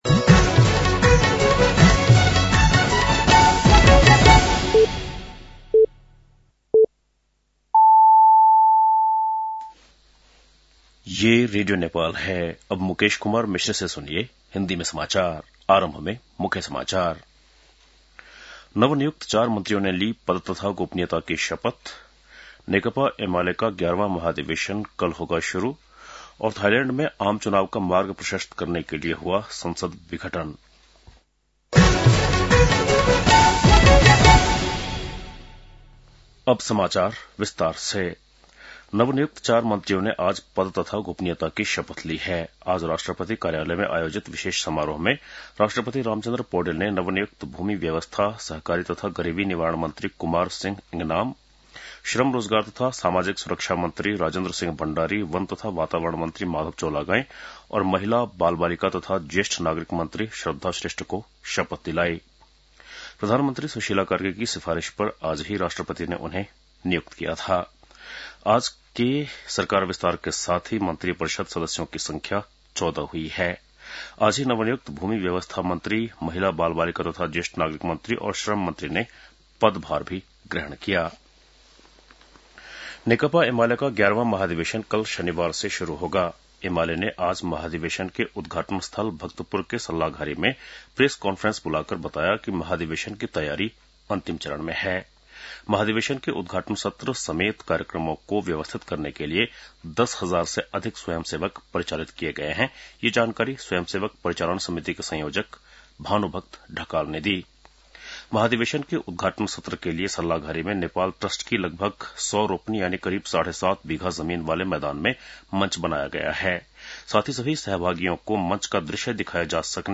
बेलुकी १० बजेको हिन्दी समाचार : २६ मंसिर , २०८२
10-pm-hindi-news-8-26.mp3